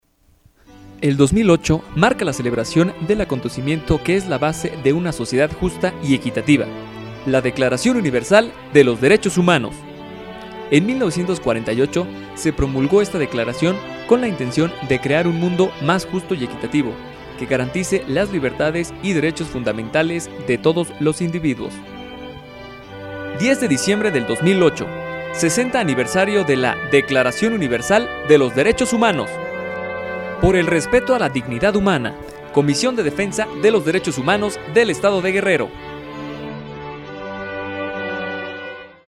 Locución profesional radiofónica, para generar las voces que necesita en su anuncio o programa de radio.